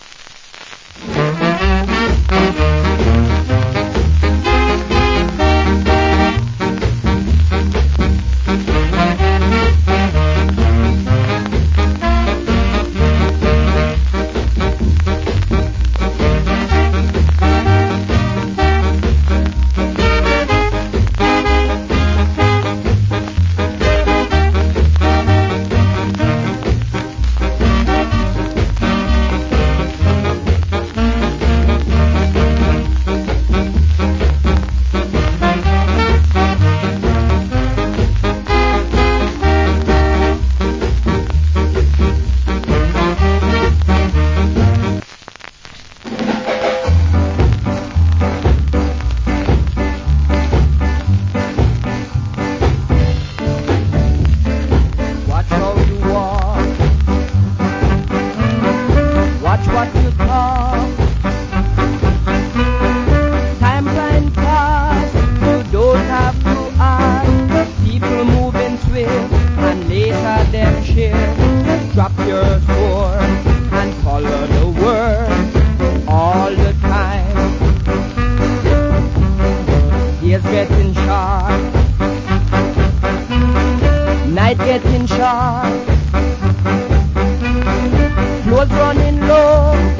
Wicked Ska Inst.